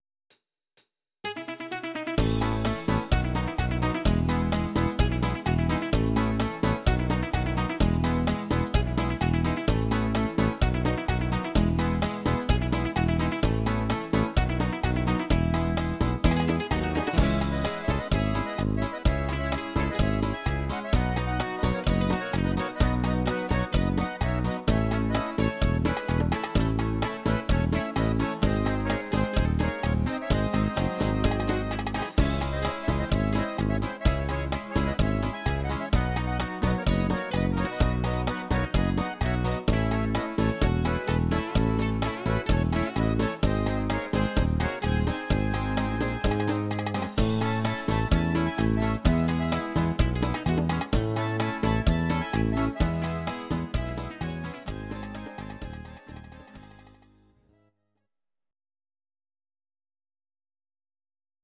These are MP3 versions of our MIDI file catalogue.
Please note: no vocals and no karaoke included.
Bachata